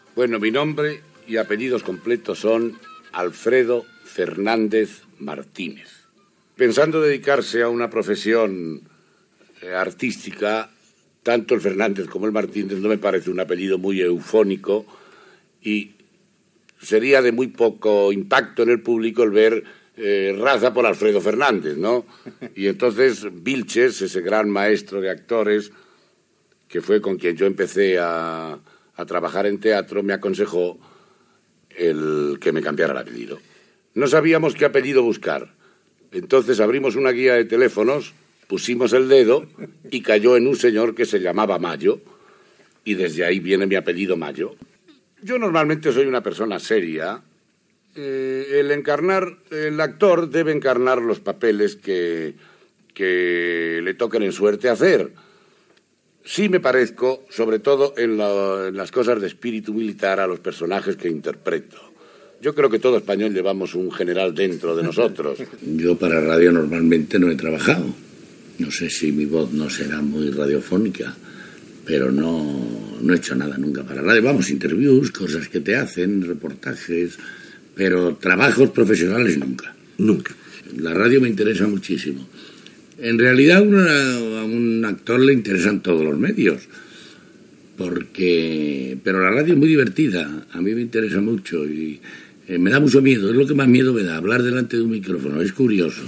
Fragment d'una entrevista a l'actor Alfedo Mayo (Alfredo Fernando Martínez)